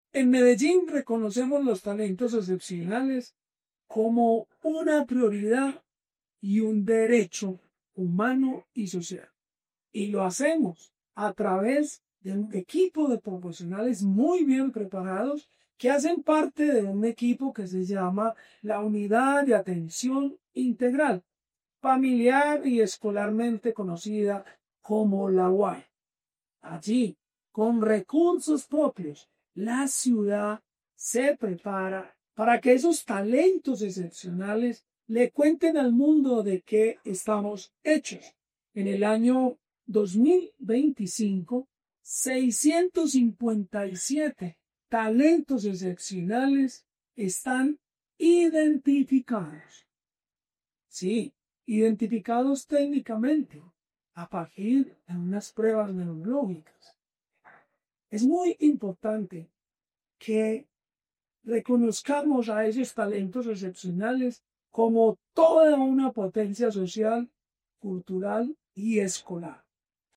Declaraciones subsecretario de la Prestación del Servicio Educativo, Jorge Iván Ríos Rivera Medellín mantiene su compromiso con la educación inclusiva al identificar y acompañar a estudiantes con capacidades y talentos excepcionales.
Declaraciones-subsecretario-de-la-Prestacion-del-Servicio-Educativo-Jorge-Ivan-Rios-Rivera-1.mp3